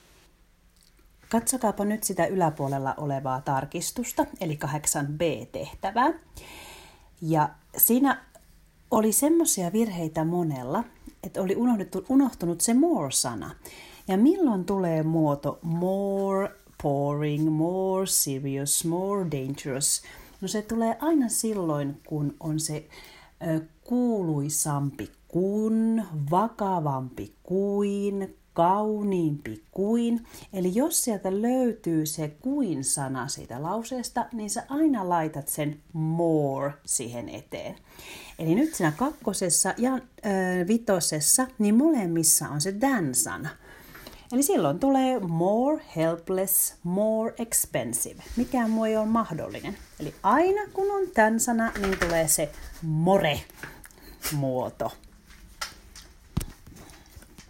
Jatketaan pitkien adjektiivien kanssa. Kuuntele ensin open neuvo!